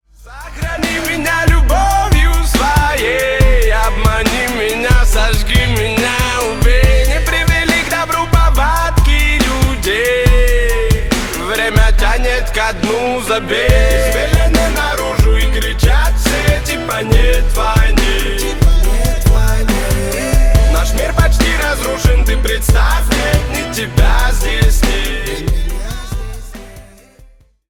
• Качество: 320 kbps, Stereo
Ремикс
Поп Музыка